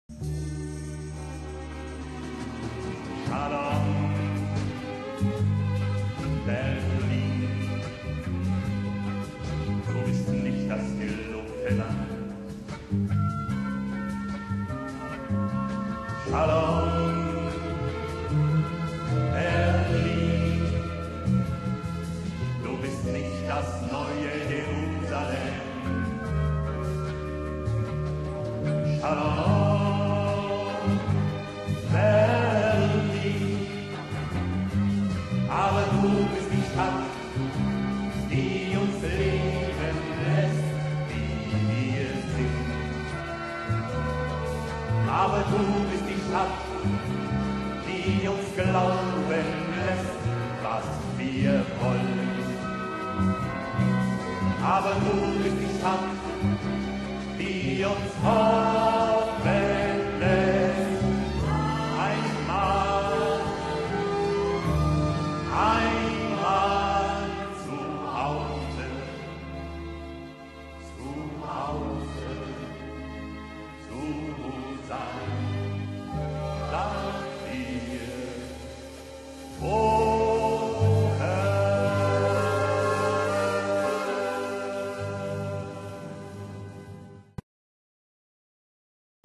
Solo-Drummer und Erzähler